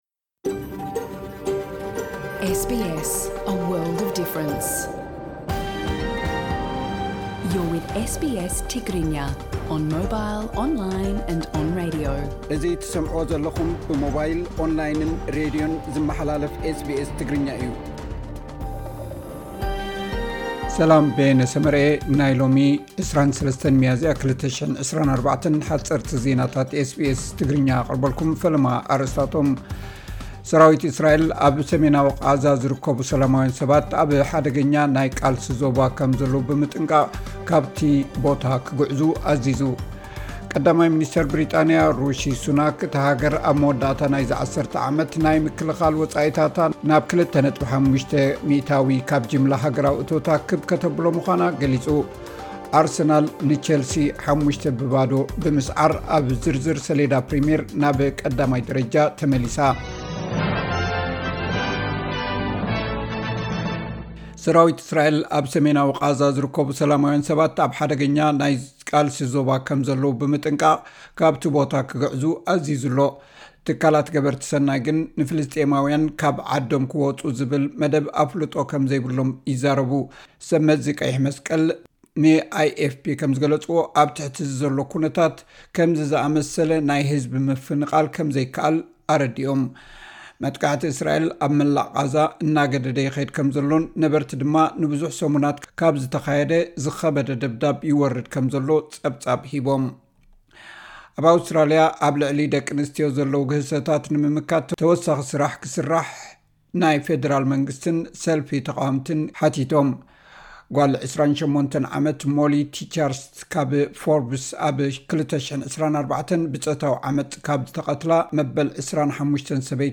ሓጸርቲ ዜናታት ኤስ ቢ ኤስ ትግርኛ (24 ሚያዝያ 2024)